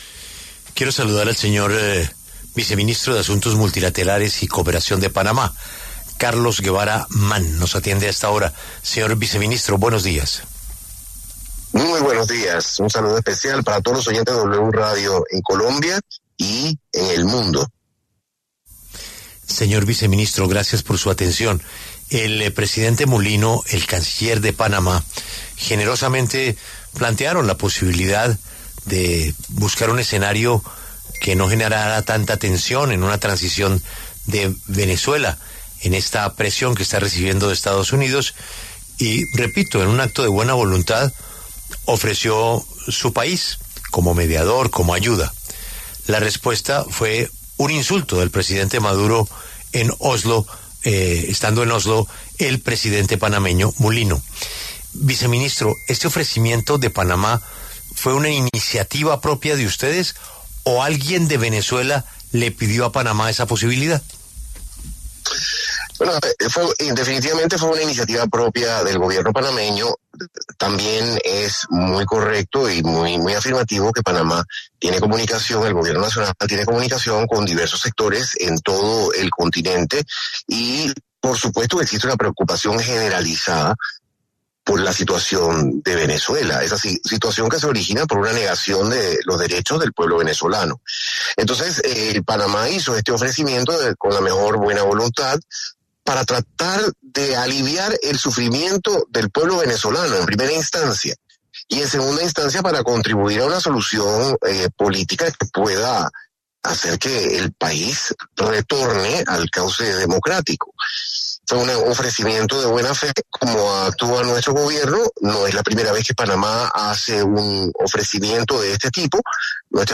Para hablar sobre el tema, pasó por los micrófonos de La W el viceministro de Asuntos Multilaterales y Cooperación de Panamá, Carlos Guevara Mann.